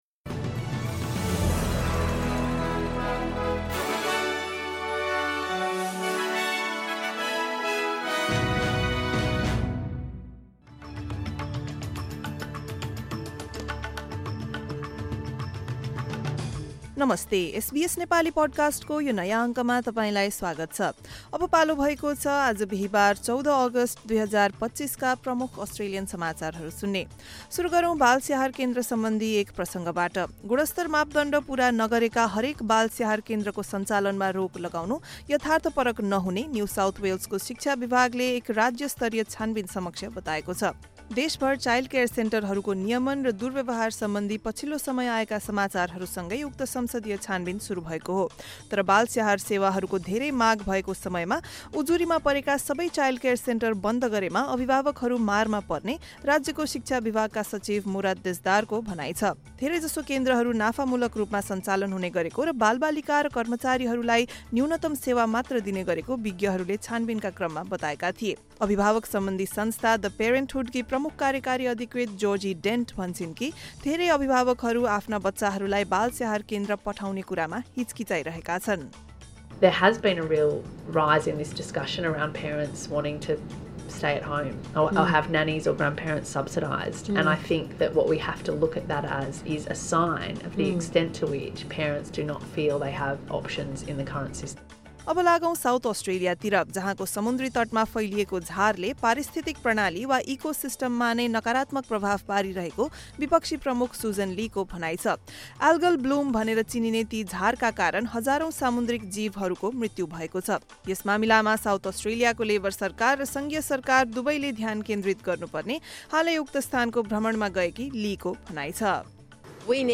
SBS Nepali Australian News Headlines: Thursday, 14 August 2025